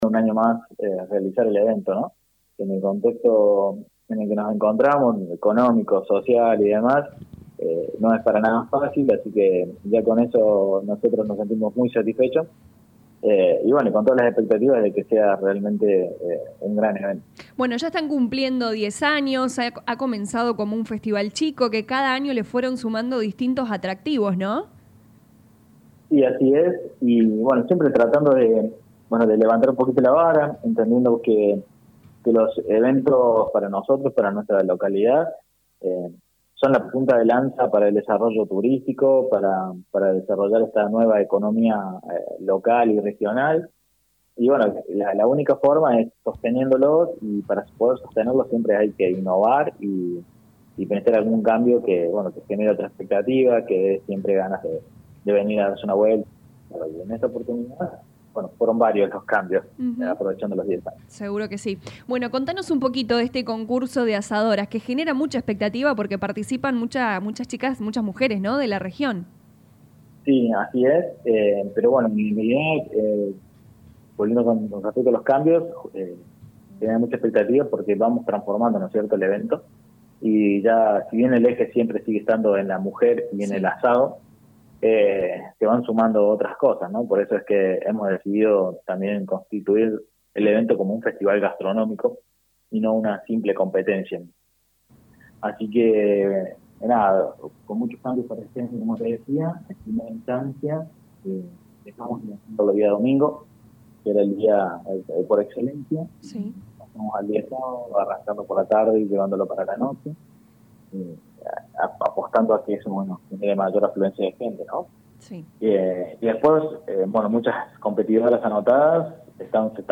El intendente de Marull Gabriel Falleto informó en diálogo con LA RADIO 102.9 FM que la cita será en el Predio del Ferrocarril de Marull, con entrada libre y gratuita, desde las 16:30 horas y hasta la noche.